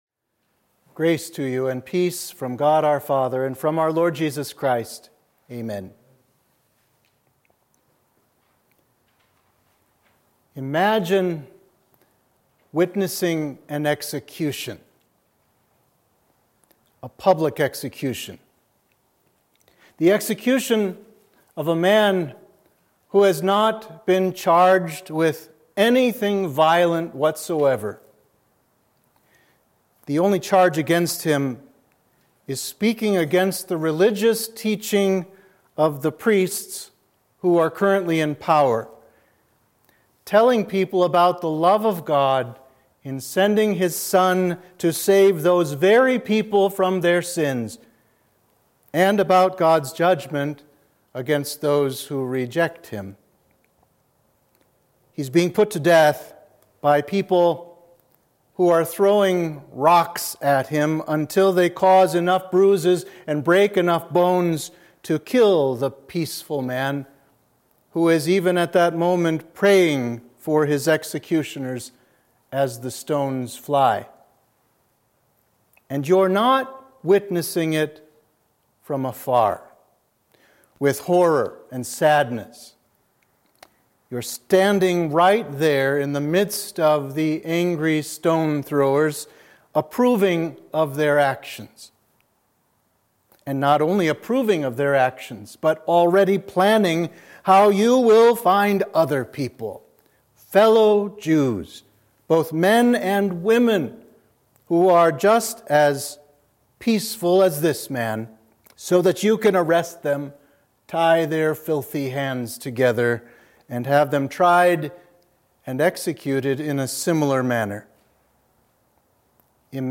Sermon for the Conversion of St. Paul